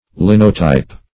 Linotype \Lin"o*type\, n. [See Line; Type.]